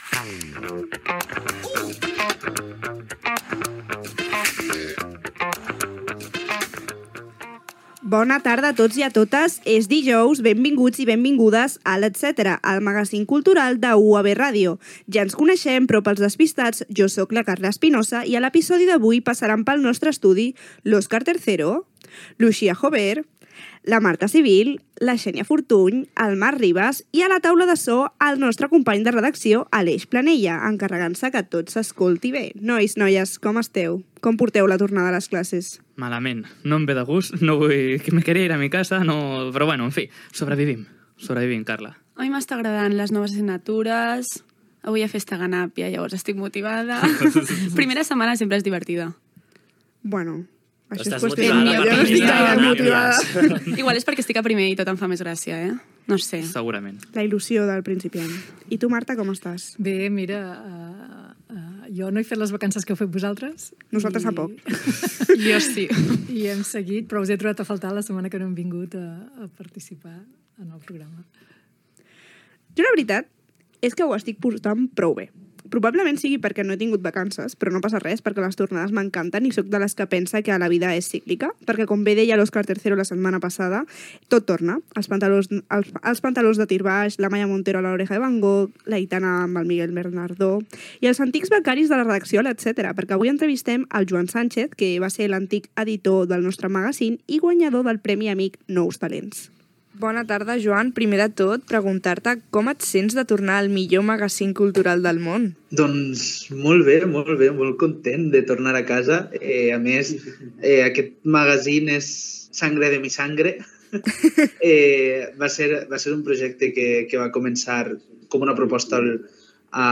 Careta del programa, comentaris inicials
Gènere radiofònic Entreteniment